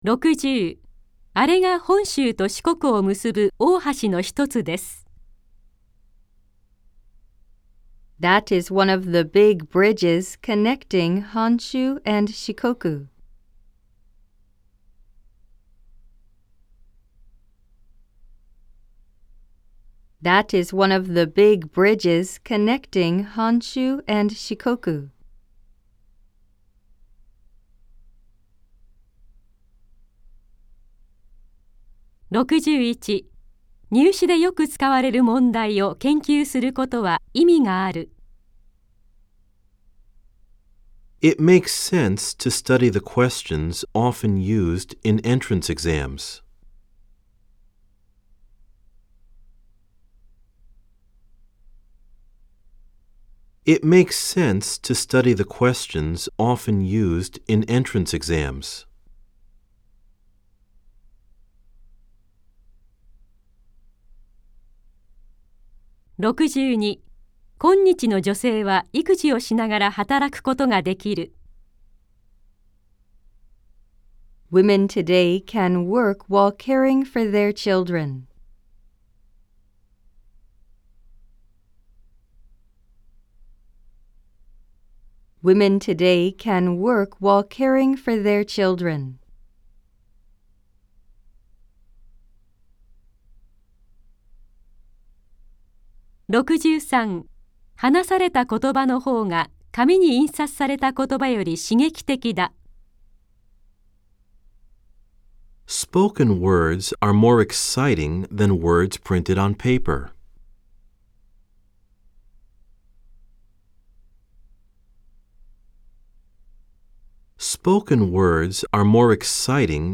（4）暗唱例文100　各章別ファイル（日本文＋英文2回読み）
※（1）（2）では英文のあとに各5秒のポーズ、（3）（4）では各7秒のポーズが入っています。